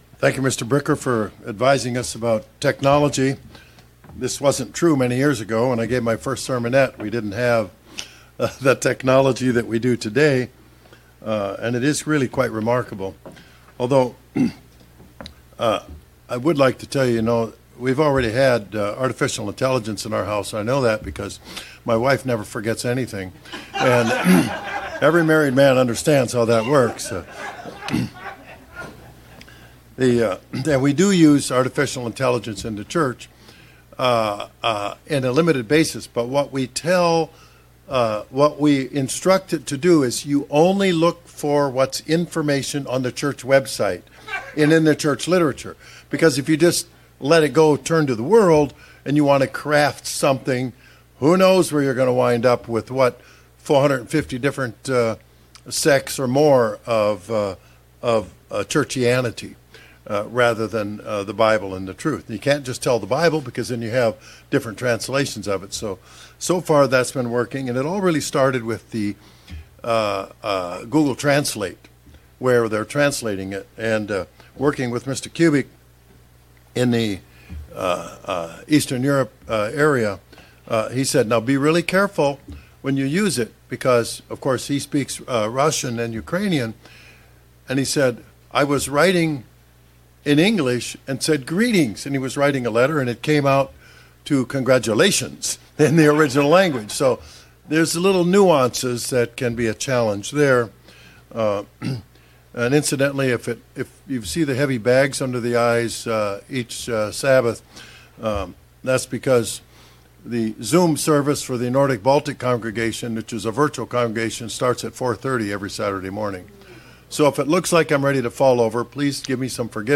In the bible there are a number of warnings. This sermon looks at seven specific warnings Jesus gave to his disciples.
Given in Springfield, MO